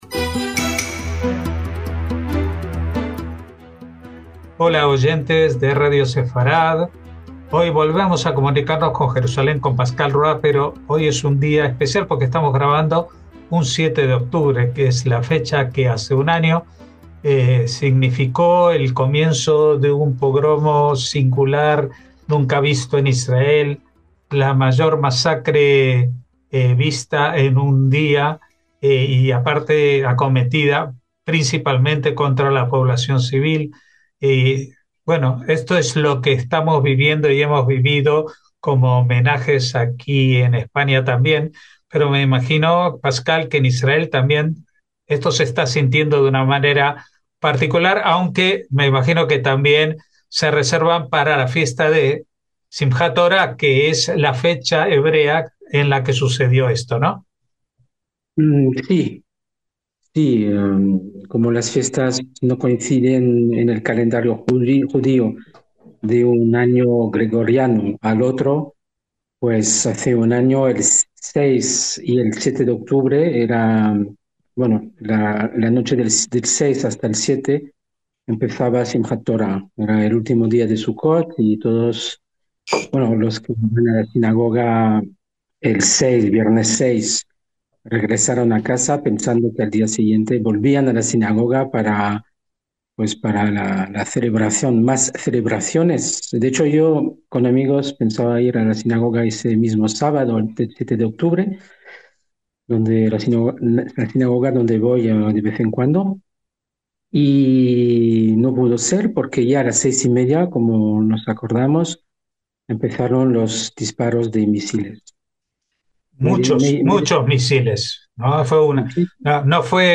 NOTICIAS CON COMENTARIO A DOS - Esta entrega de las noticias es singular, su grabación tiene lugar el 7 de octubre, cuando se cumple un año gregoriano del pogromo protagonizado por Hamás contra la población israelí.